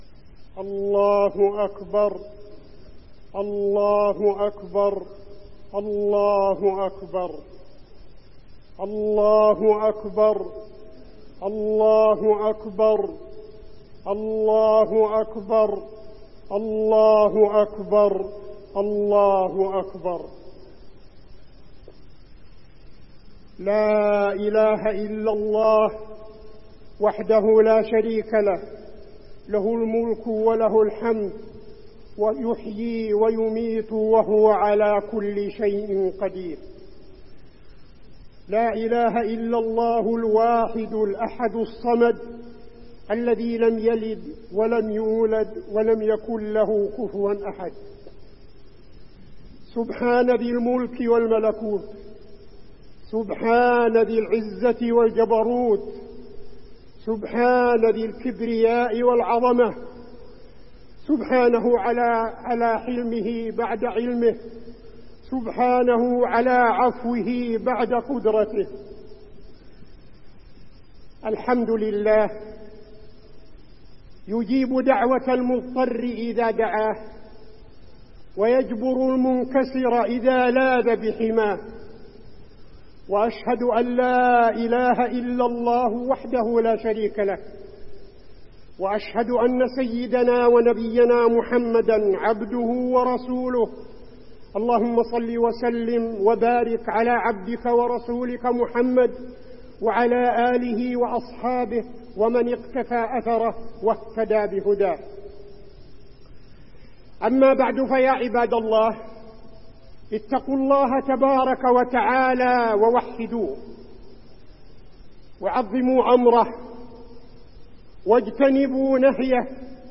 خطبة الاستسقاء - المدينة- الشيخ عبدالعزيز بن صالح
تاريخ النشر ١٢ صفر ١٤٠٥ هـ المكان: المسجد النبوي الشيخ: فضيلة الشيخ عبدالعزيز بن صالح فضيلة الشيخ عبدالعزيز بن صالح خطبة الاستسقاء - المدينة- الشيخ عبدالعزيز بن صالح